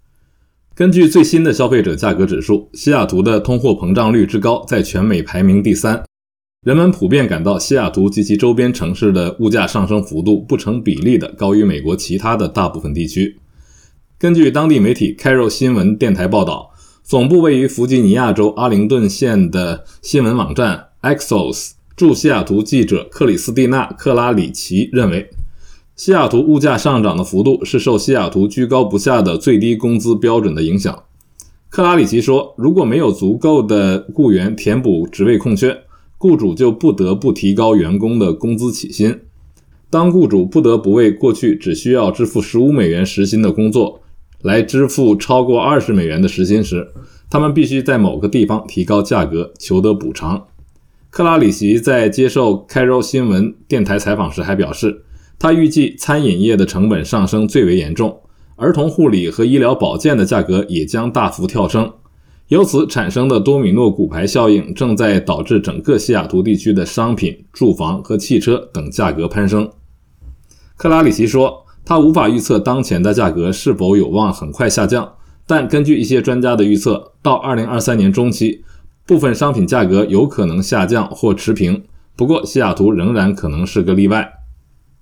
每日新聞